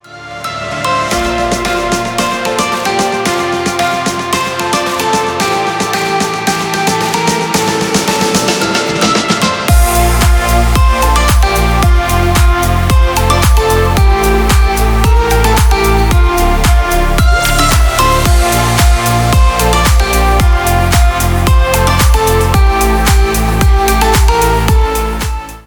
Электроника
клубные # без слов